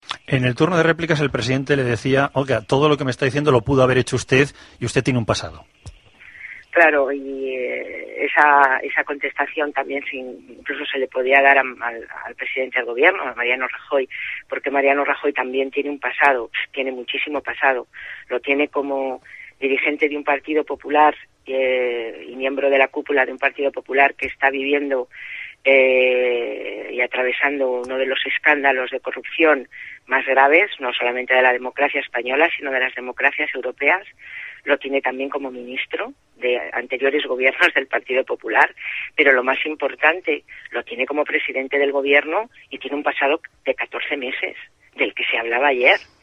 Soraya Rodríguez en la Cope el 21/2/2013